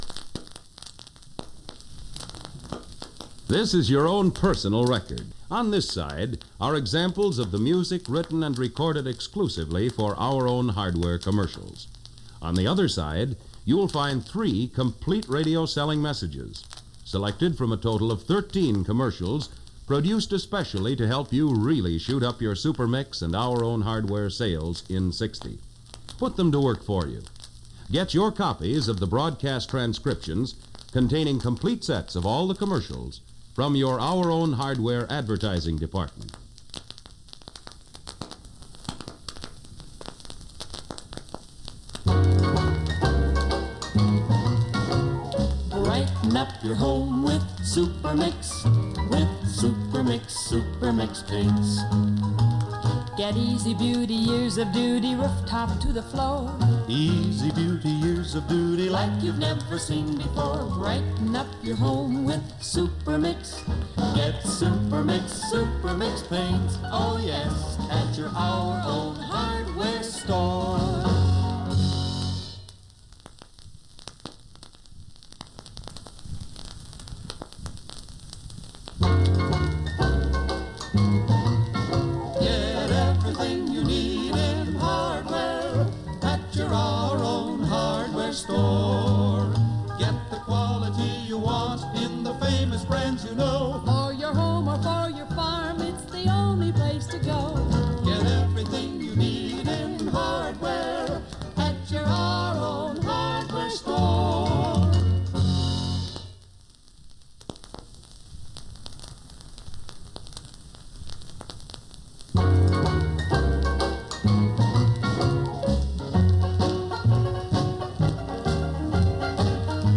Made from a phonograph record.